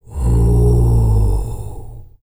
TUVANGROAN09.wav